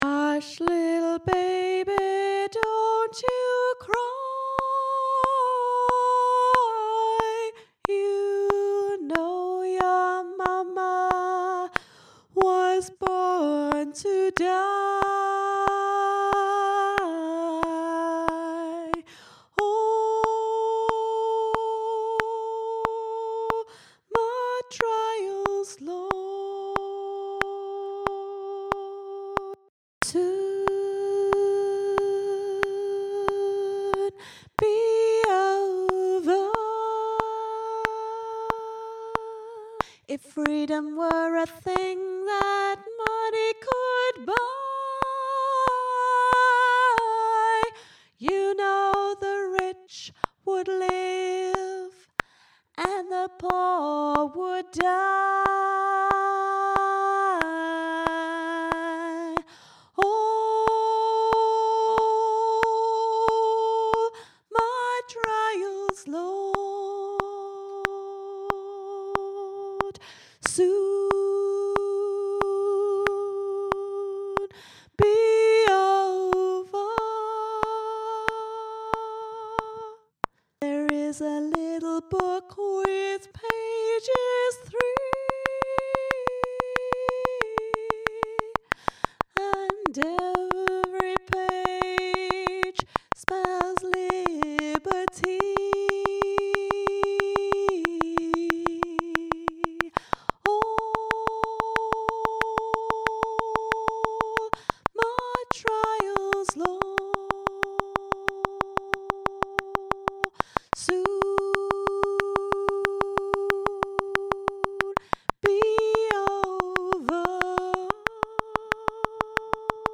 all-my-trials-all-my-trials-soprano
all-my-trials-all-my-trials-soprano.mp3